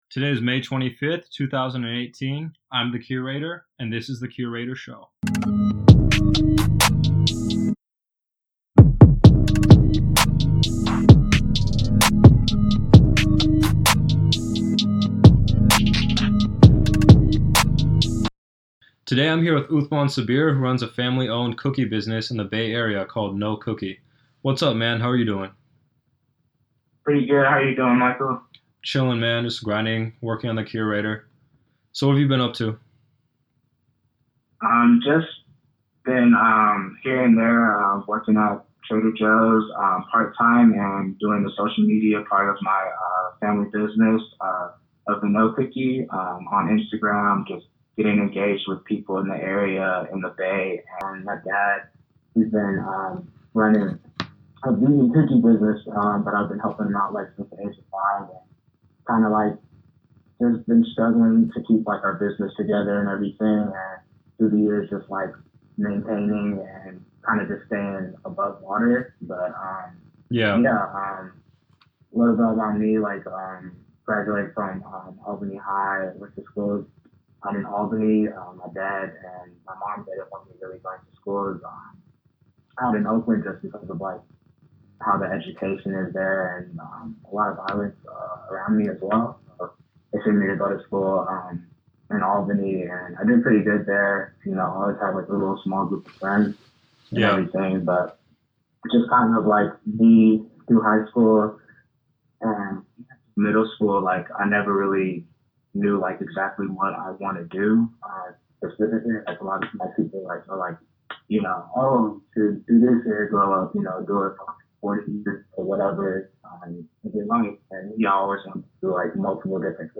The interview was great and I actually decided to make it more than just a test run.